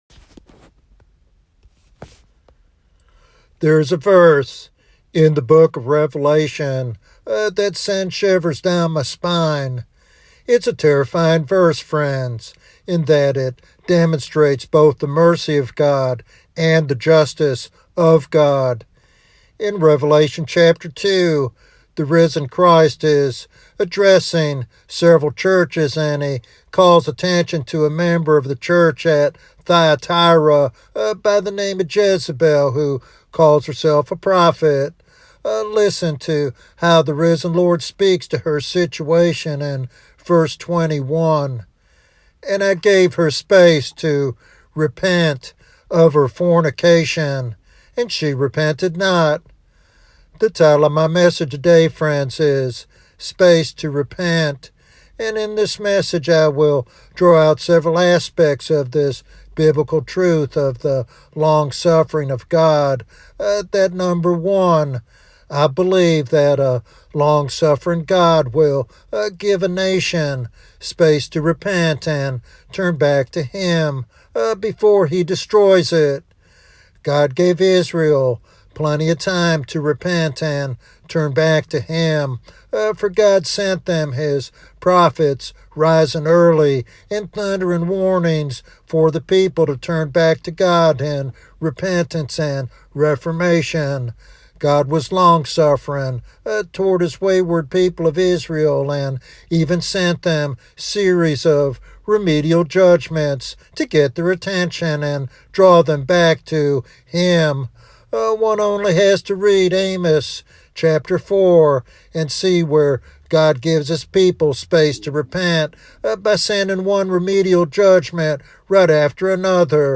This sermon serves as a solemn call to return to God with sincere hearts while there is still opportunity.